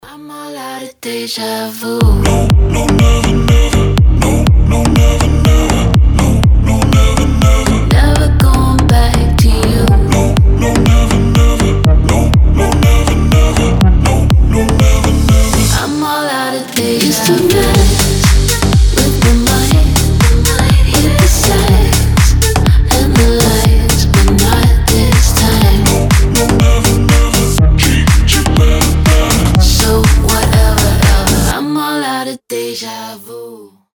• Качество: 320, Stereo
deep house
женский голос
slap house
Качественная танцевальная музыка на звонок телефона